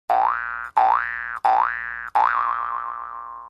Play, download and share toink original sound button!!!!
toink.mp3